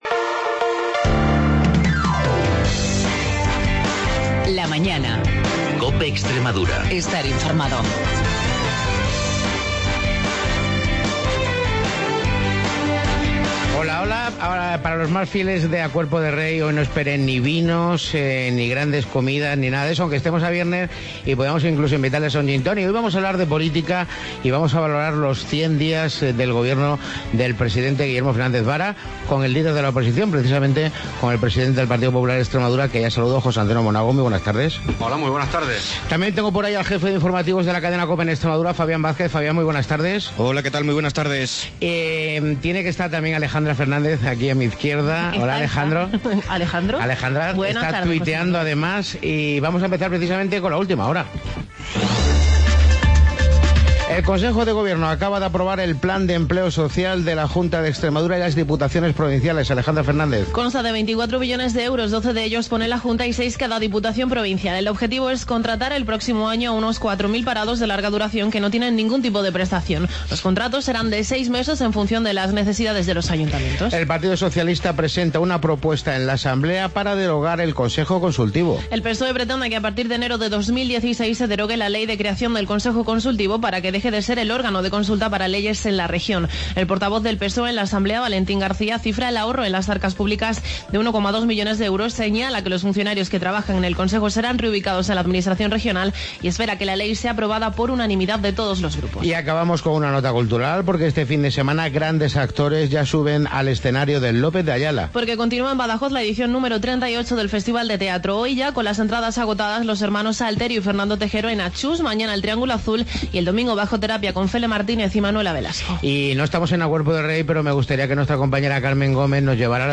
Entrevista José Antonio Monago 23 Octubre (100 días Fdez Vara)